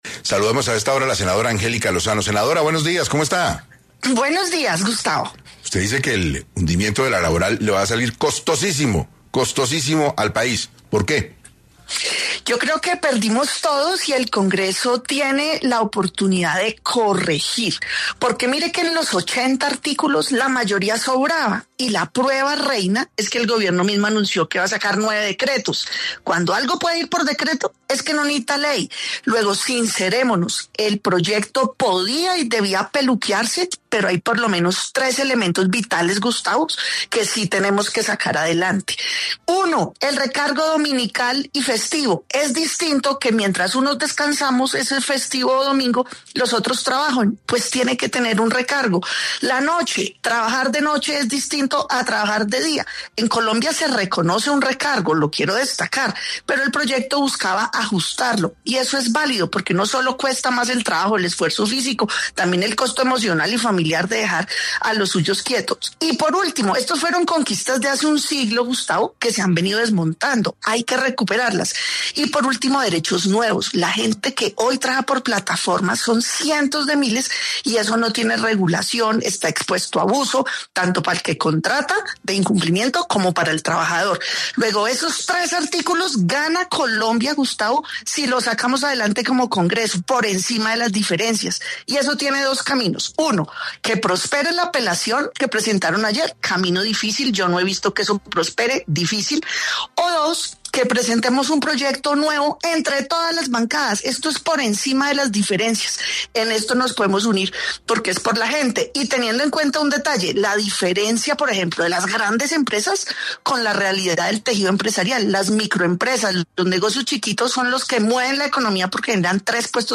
En 6AM habló Angélica Lozano, senadora, acerca de la importancia de la reforma laboral para los ciudadanos y su hundimiento en el Congreso.
En 6AM de Caracol Radio, la senadora Angélica Lozano brindó su opinión con respecto a la reforma laboral y la importancia que está tiene a la hora de hablar de recargos nocturnos, horarios y beneficios para los trabajadores del país.